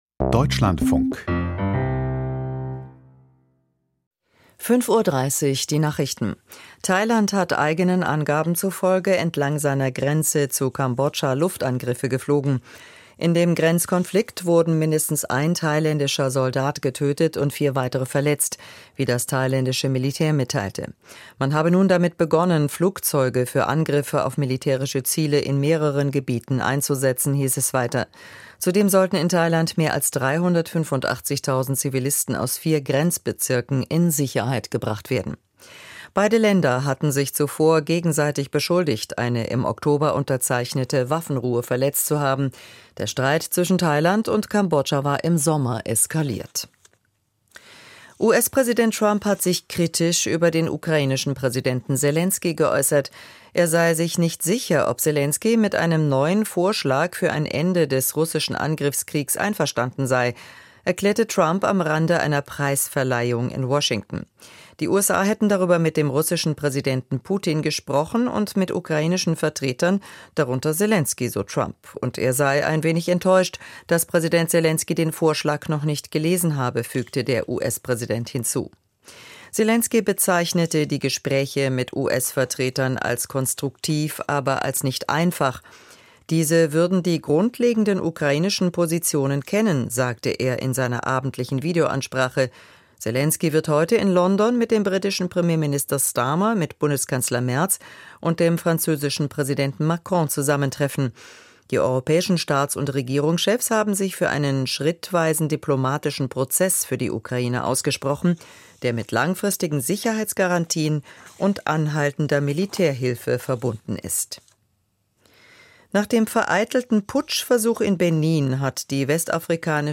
Die Nachrichten vom 08.12.2025, 05:30 Uhr